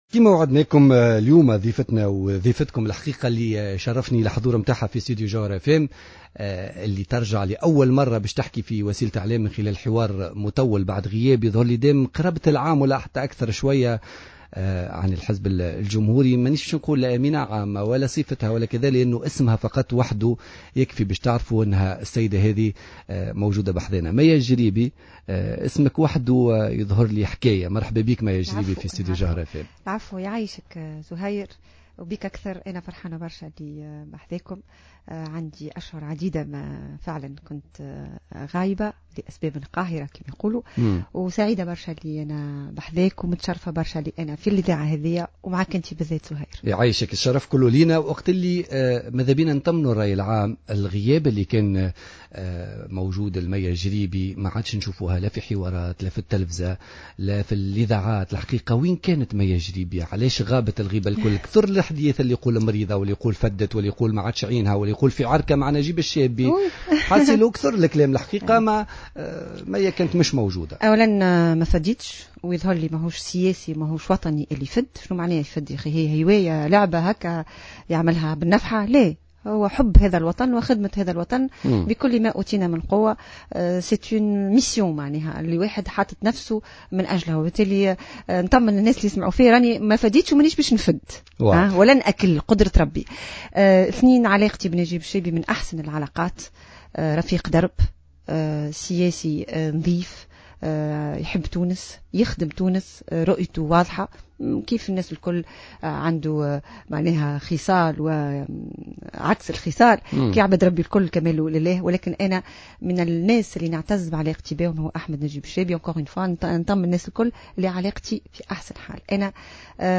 أكدت الامينة العامة للحزب الجمهوري مية الجريبي ضيفة بوليتيكا اليوم الثلاثاء 4 اوت 2015 أن كانت مسنودة من قبل الاعلاميين خلال فترة مرضها ومن قبل السياسيين والتونسيين ككل.